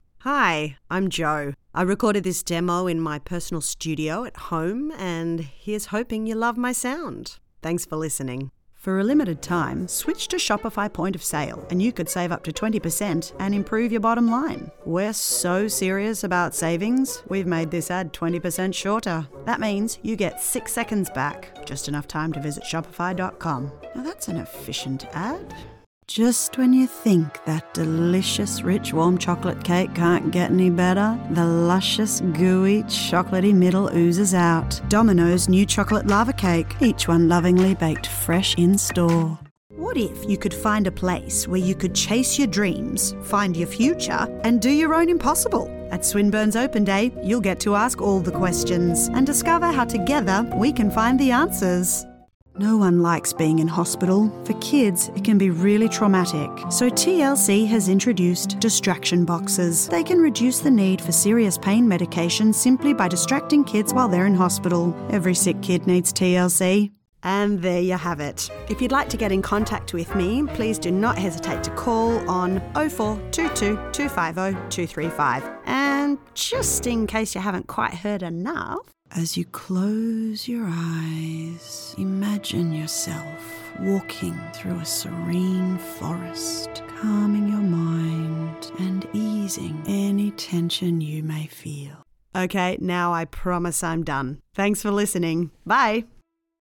English (Australian)
I am an Aussie female, with a trustworthy, conversational and down-to-earth sound.
Conversational
Trustworthy
Friendly